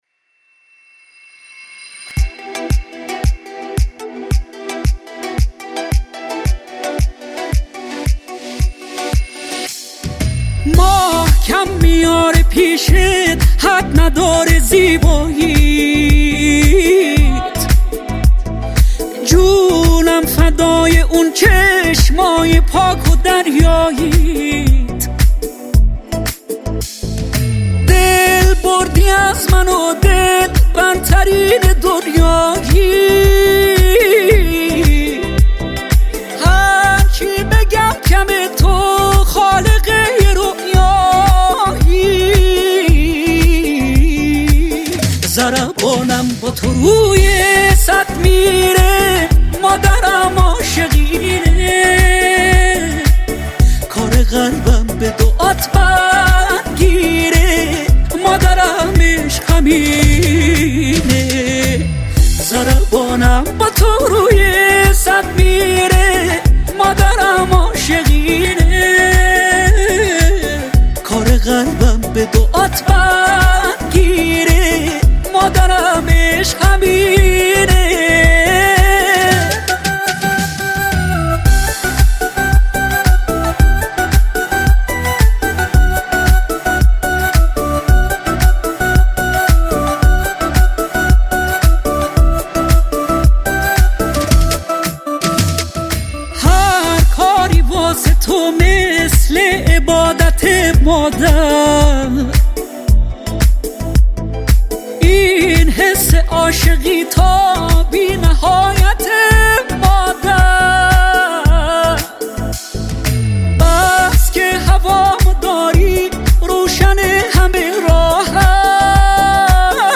سبک : موسیقی پاپ